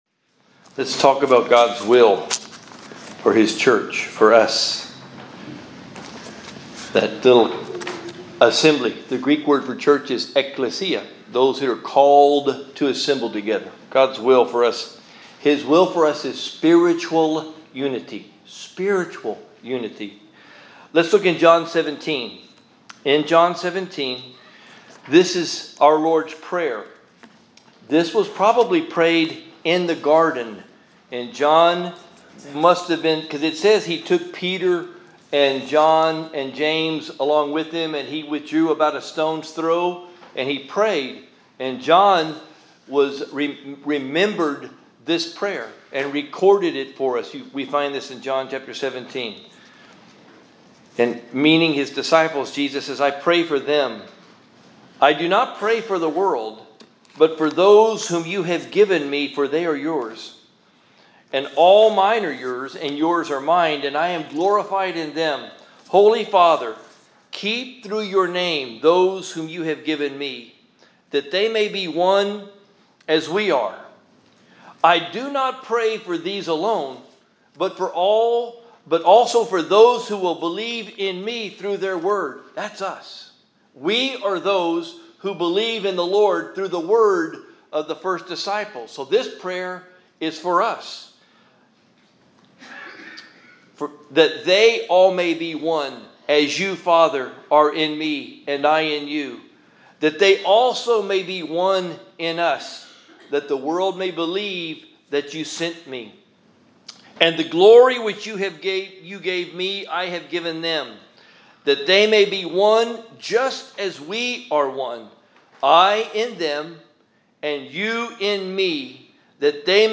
Sunday Message - University Park Baptist